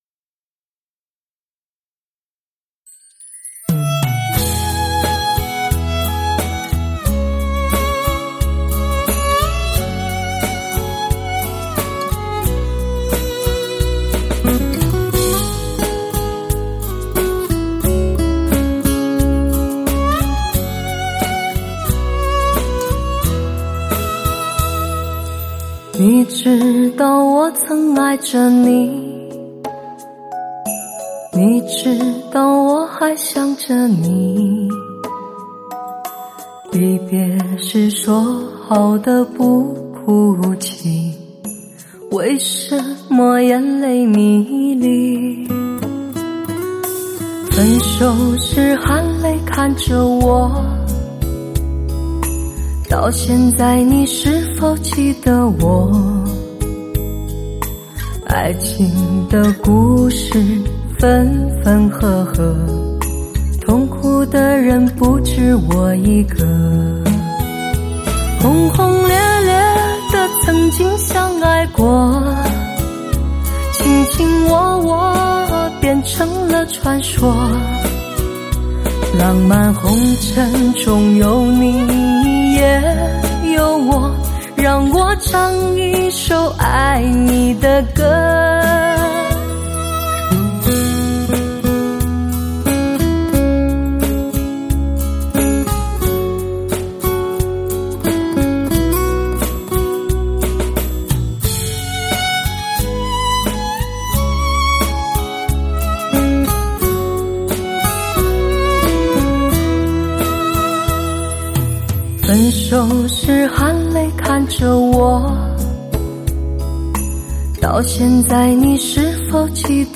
一辑不容错失的声色魅力天碟，炙手可热的流行HI-FI潮曲，倍具声色感染力的至爱靓声。
人声结像凸浮的高临场感音质，突破流行乐的高品味聆听态度，专业发烧唱片制作团队全新打造！